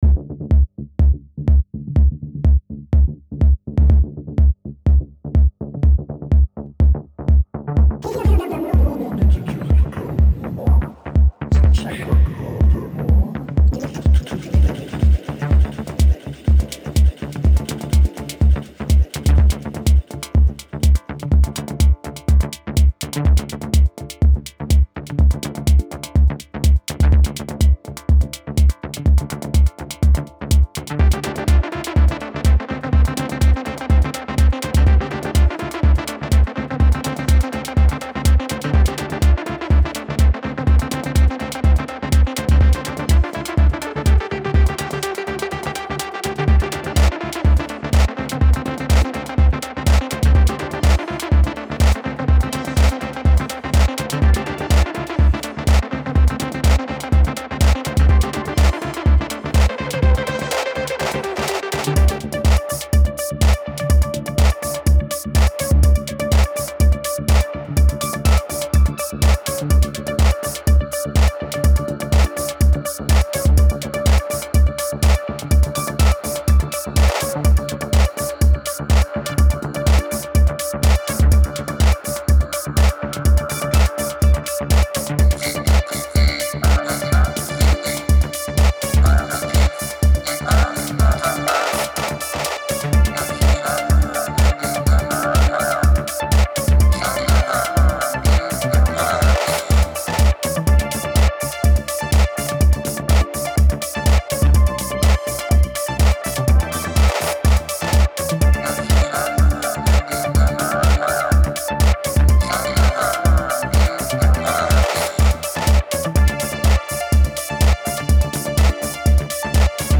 Maybe it's good to tell that the end of the track is meant to be mixed into another track.